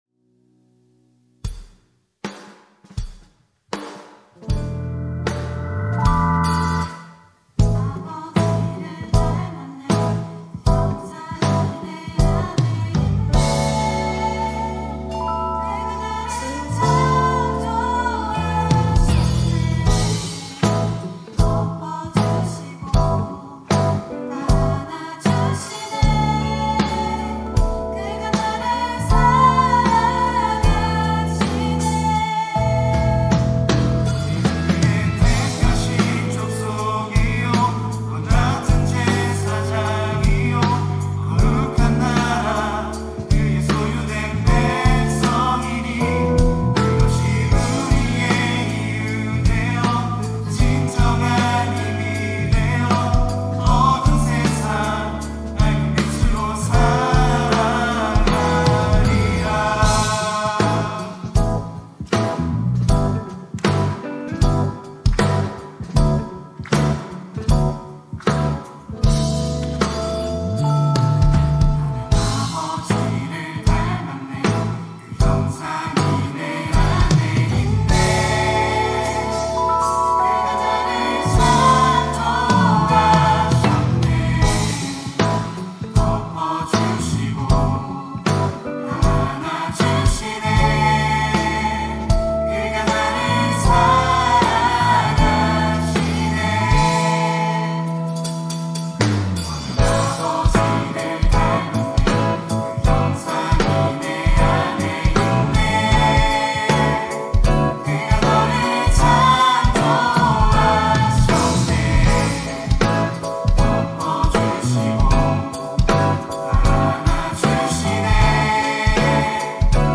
특송과 특주 - 나는 아버지를 닮았네